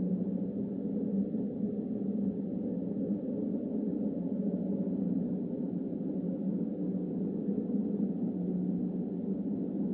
Windloop2.wav